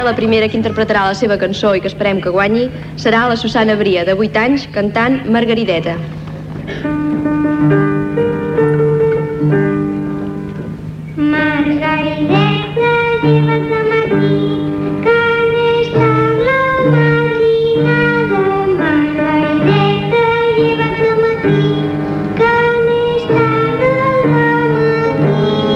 Transmissió del I Festival de la Cançó Infantil de Lleida.
Musical